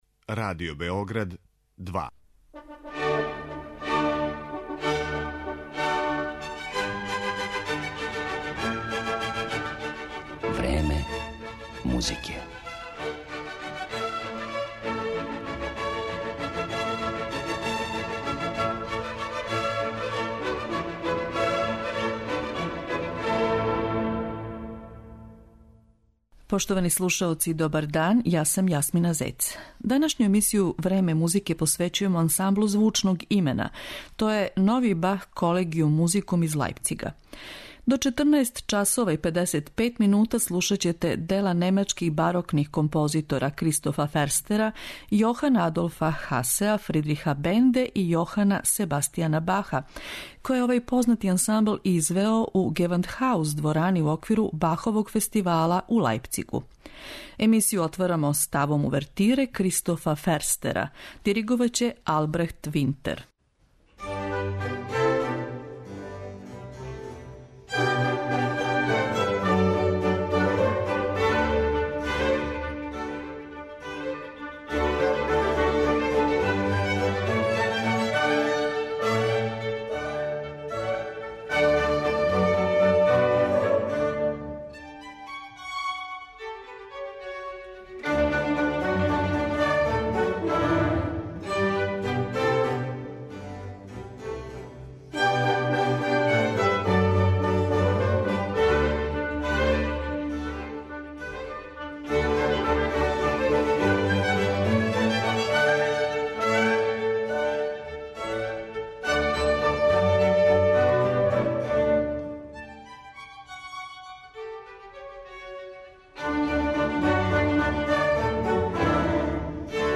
Ансамбл 'Нови Бах Колегијум музикум'